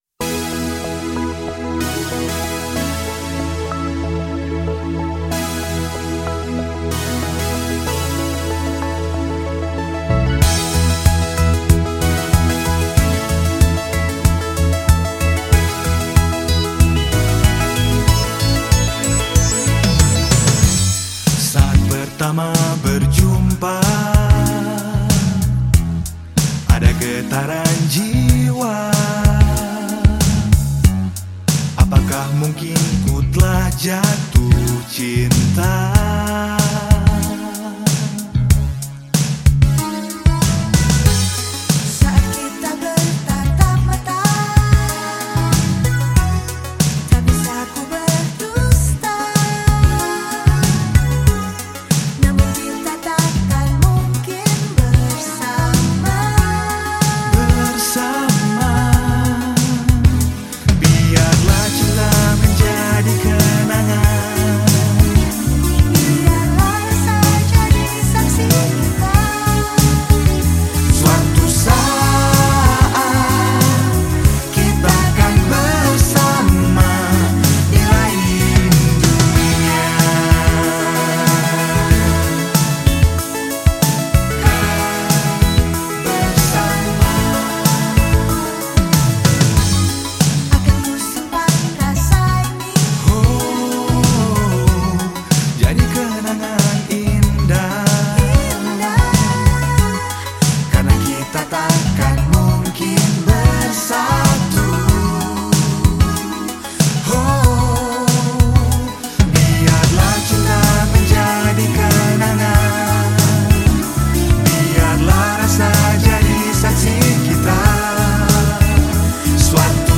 Jakarta Electronic
lagu balada pop duet